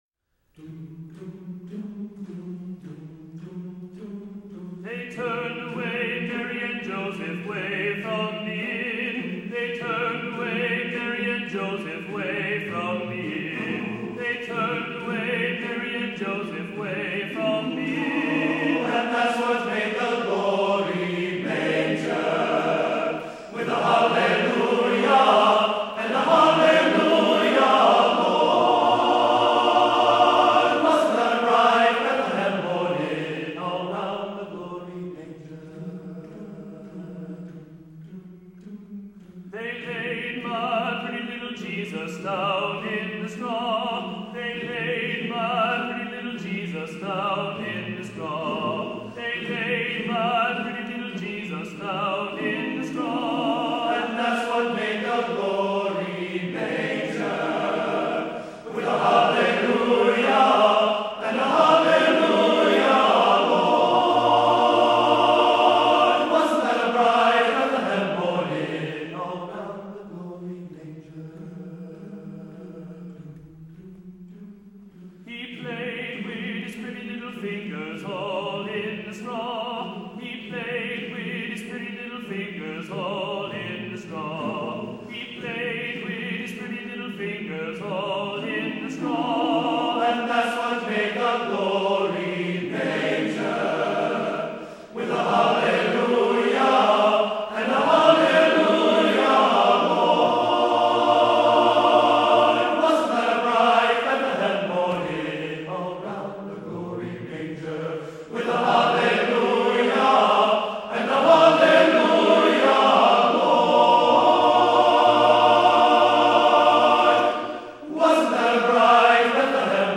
Composer: Spirituals
Voicing: TTBB a cappella